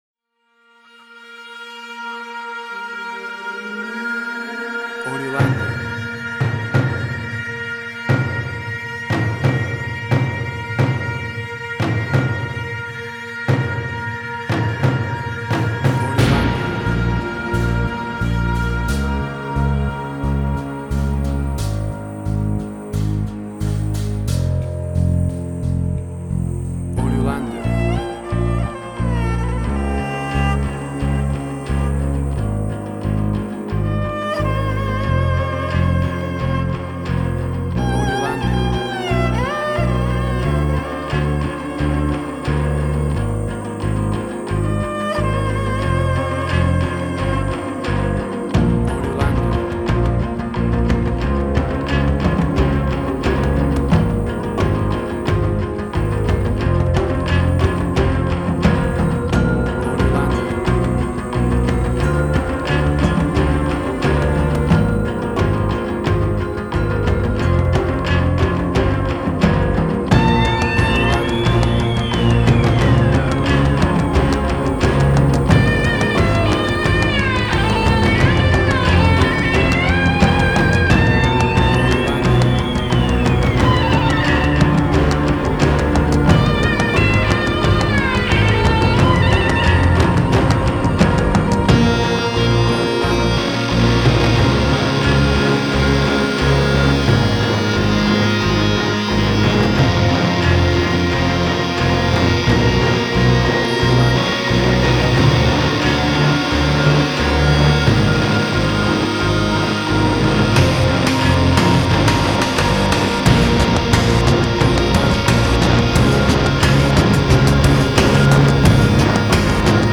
Cinematic Industrial Sci-fi
Tempo (BPM): 89